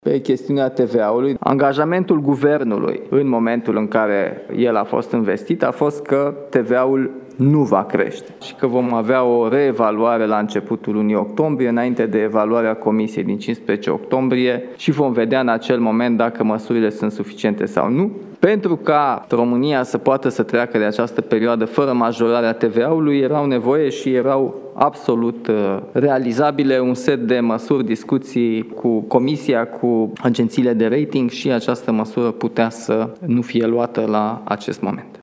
Președintele Nicușor Dan a susținut luni o conferință de presă la Palatul Cotroceni.